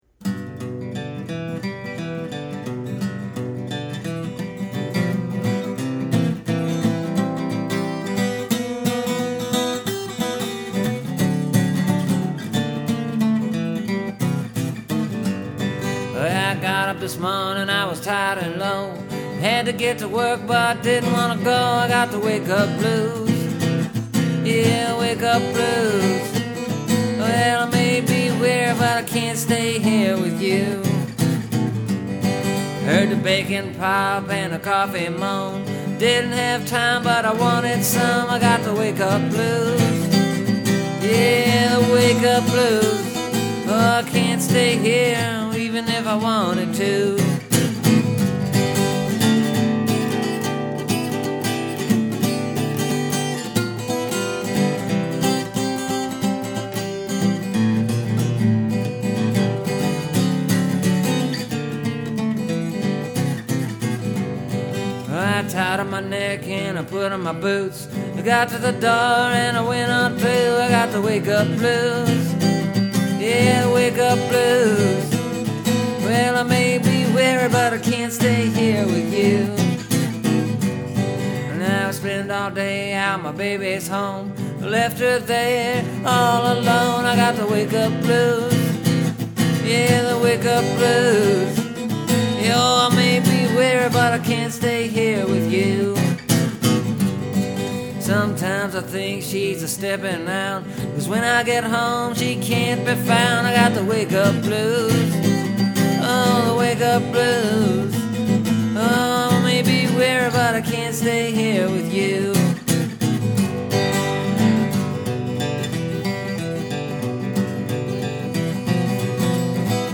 It’s also more of a traditional-sounding blues song, which I don’t really write as much anymore.